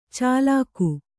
♪ cālāku